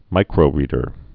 (mīkrō-rēdər)